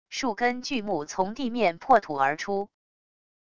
数根巨木从地面破土而出wav音频